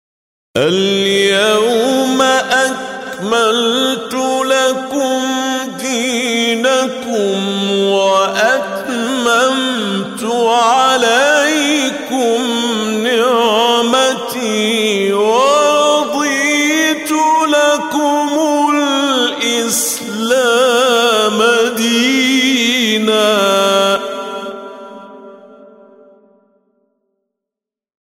اليوم أكملت لكم دينكم _ التحقيق